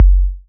edm-kick-84.wav